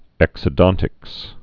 (ĕksə-dŏntĭks)